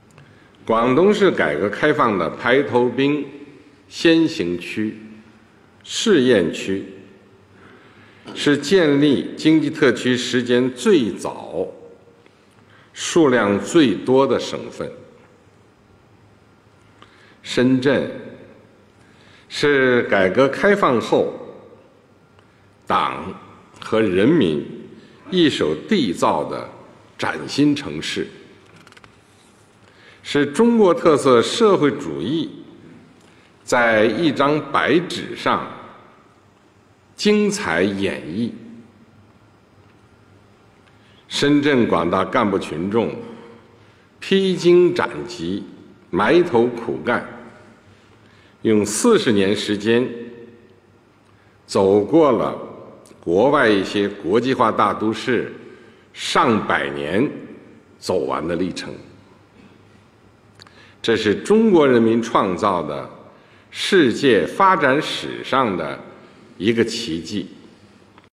今天，深圳经济特区建立40周年庆祝大会在广东省深圳市隆重举行，中共中央总书记、国家主席、中央军委主席习近平出席大会并发表重要讲话。
讲话金句